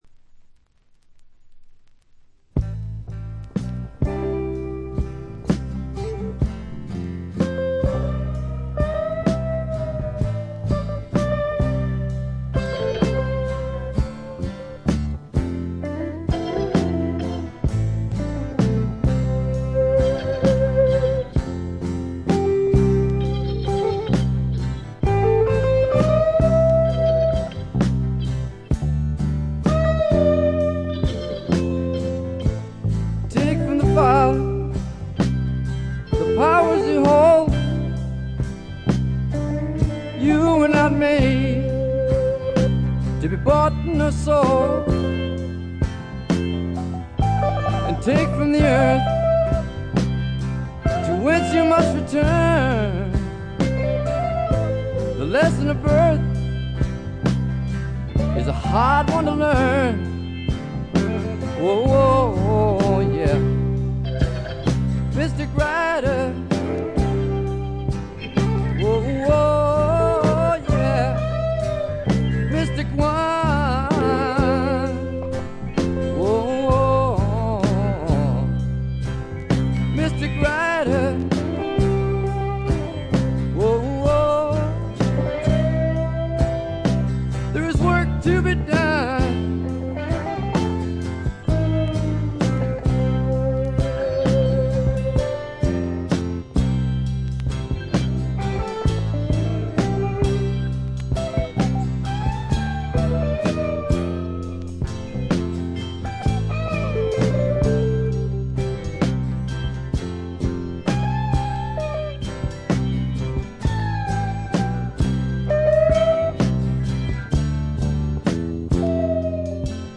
中身はヴォーカルも演奏も生々しくラフなサウンドがみっちり詰まっている充実作で、名盤と呼んでよいでしょう。
試聴曲は現品からの取り込み音源です。